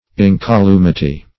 Search Result for " incolumity" : The Collaborative International Dictionary of English v.0.48: Incolumity \In`co*lu"mi*ty\, n. [L. incolumitas, fr. incolumis uninjured, safe; perh. fr. in intens.